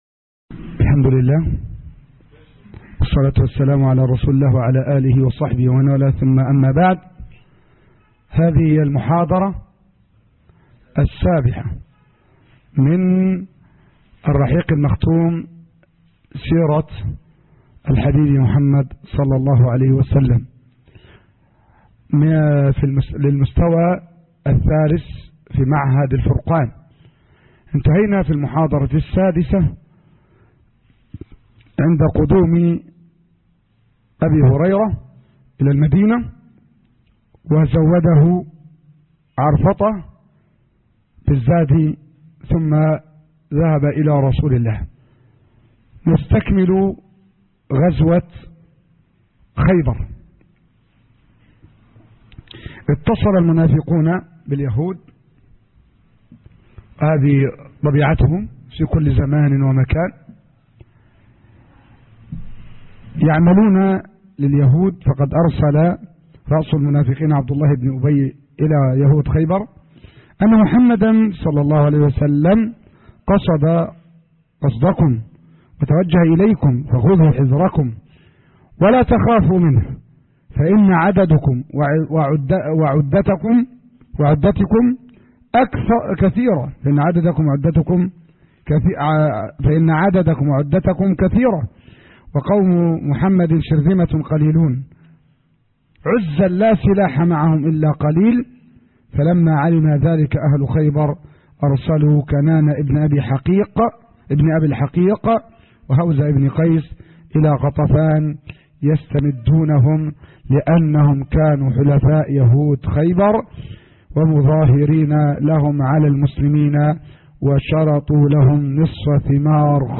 الدرس _7_ غزوة خيبر (الرحيق المختوم )